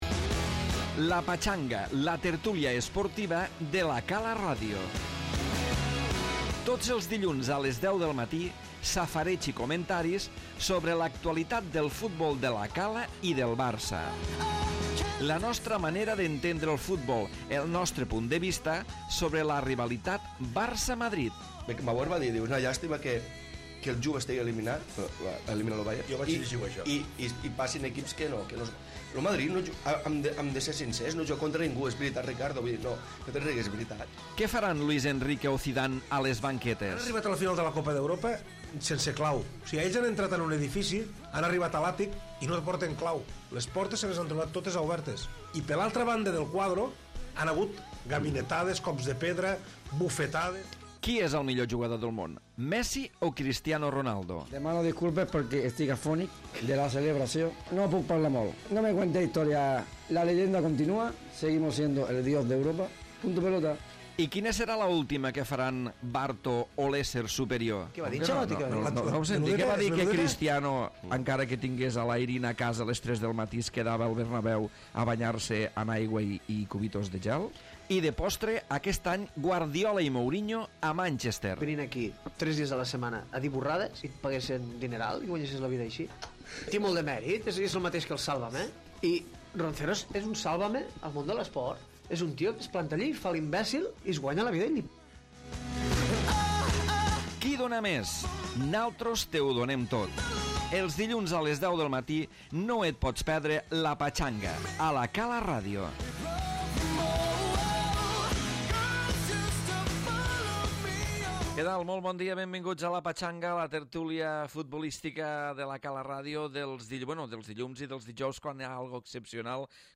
Tertúlia futbolística pujada de tensió per les actuacions arbitrals i anàlisi de la jornada futbolística